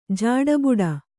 ♪ jāḍa buḍa